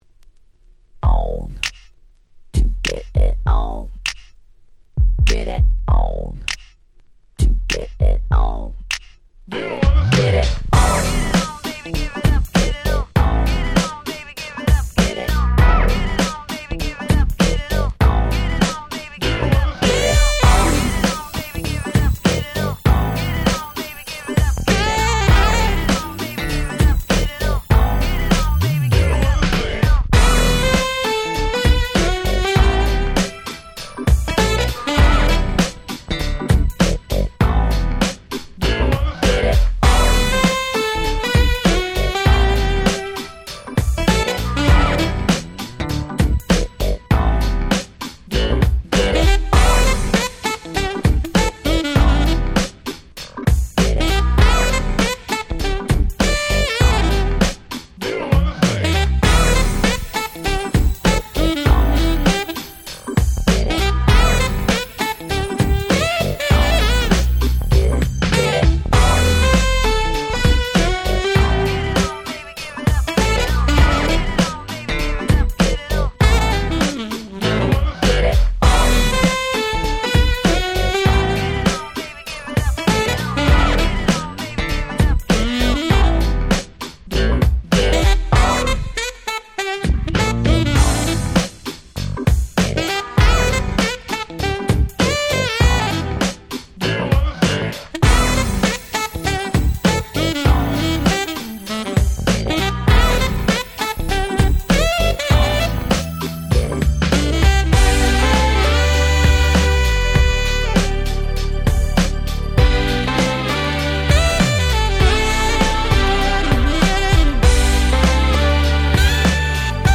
97' Nice Acid Jazz !!
Hip Hop Beatに彼女のご機嫌なSaxが縦横無尽に鳴り響く最高に格好良い1曲！！
サックス アシッドジャズ